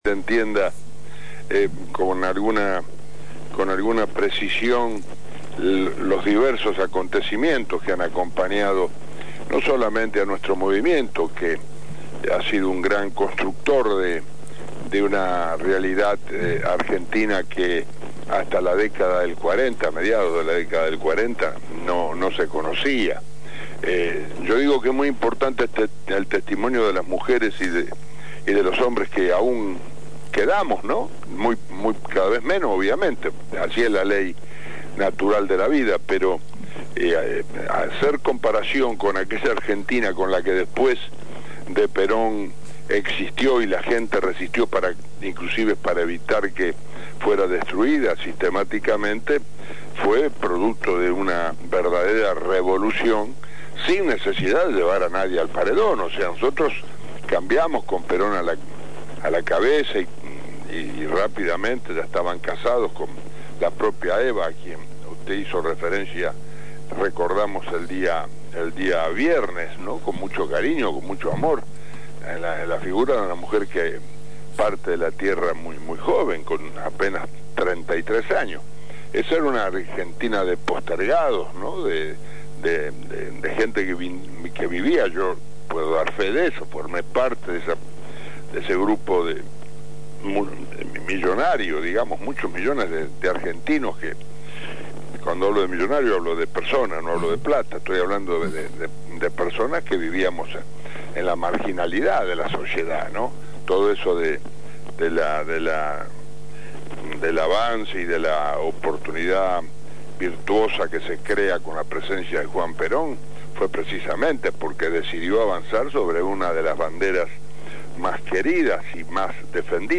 Lorenzo Pepe, Secretario General del Instituto Juan Domingo Perón y ex dirigente de la Unión Ferroviaria, habló en Los Domingos no son Puro Cuento en ocasión de la conmemoración del fallecimiento de Evita el pasado viernes 26 de Julio.